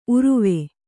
♪ uruve